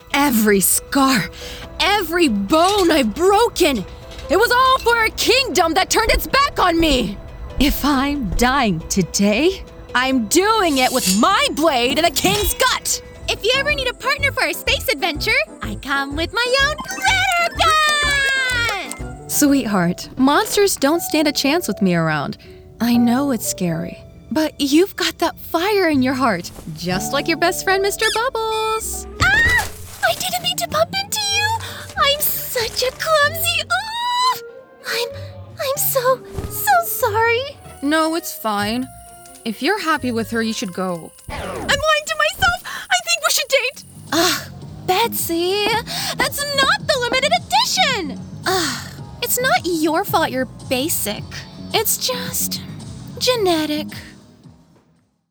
Crafting engaging personalities, specializing in young adult roles with authentic emotion and versatility.
Professionally Treated Vocal Booth